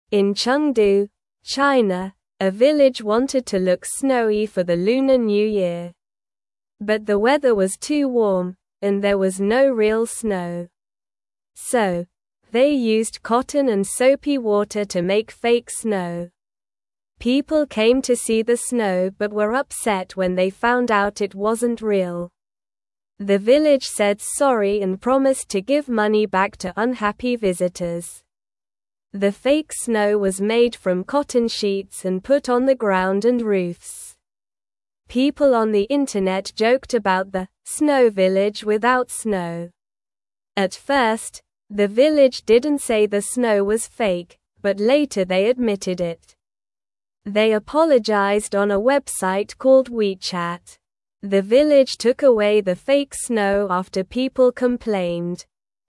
Slow
English-Newsroom-Beginner-SLOW-Reading-Village-Makes-Fake-Snow-for-Lunar-New-Year-Fun.mp3